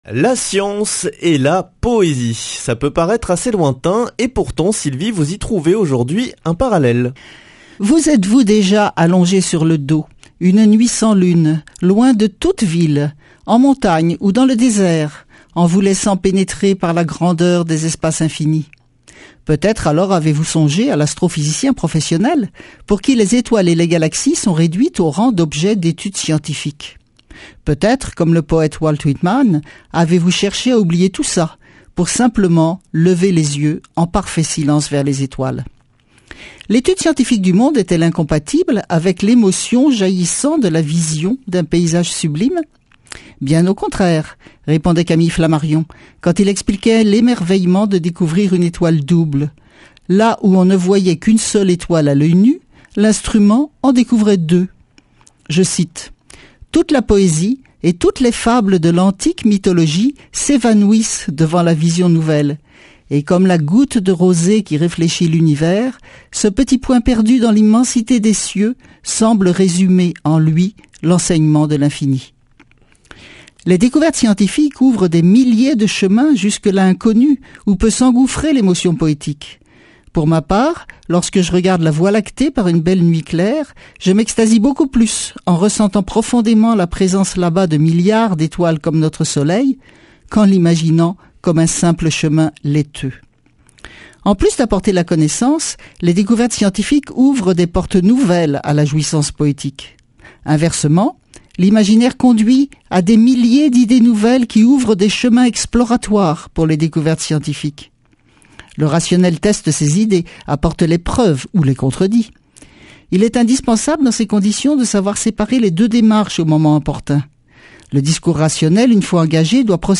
Speech
Astrophysicienne